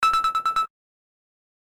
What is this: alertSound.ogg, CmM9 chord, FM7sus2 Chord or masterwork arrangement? alertSound.ogg